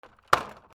空き瓶のケースを積む
/ J｜フォーリー(布ずれ・動作) / J-14 ｜置く
『タン』